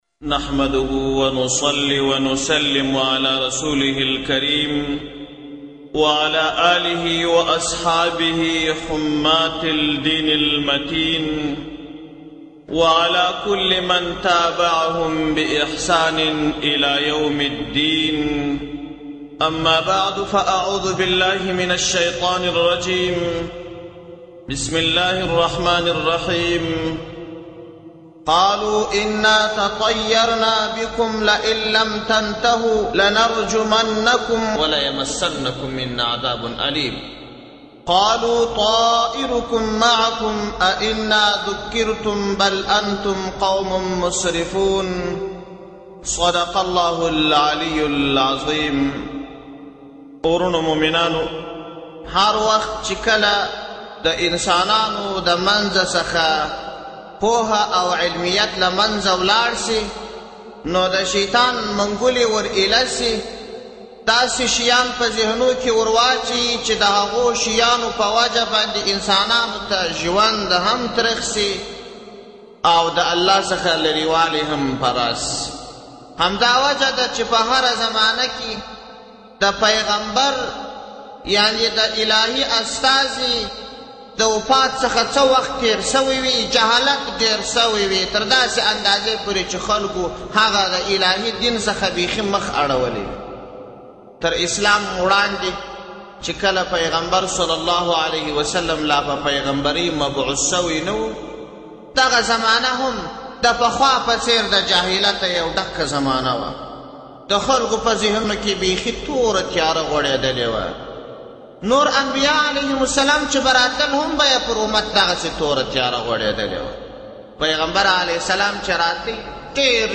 ددې اصلاحي بیان دکښته کولو لپاره لاندې لېنک کښېکاږی